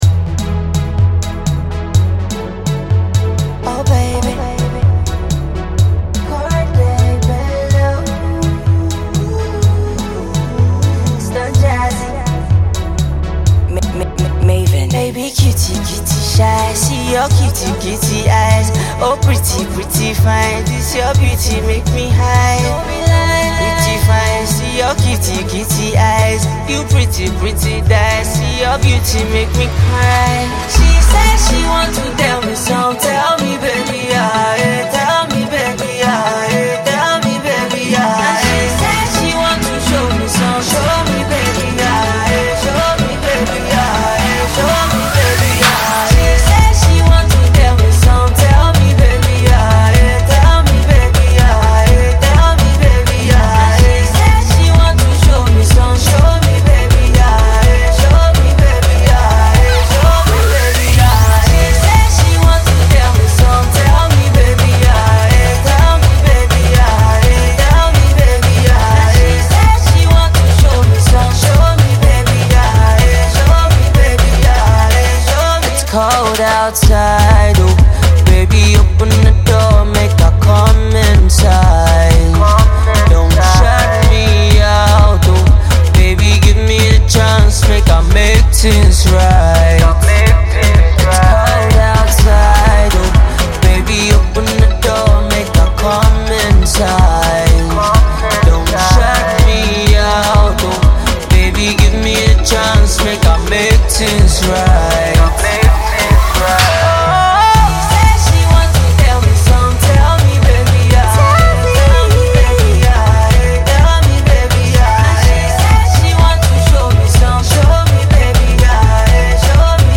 tenor
afro-pop